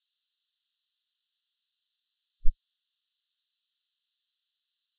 silence_loop.wav